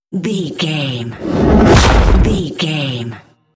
Whoosh to hit trailer
Sound Effects
Fast paced
In-crescendo
Atonal
dark
intense
tension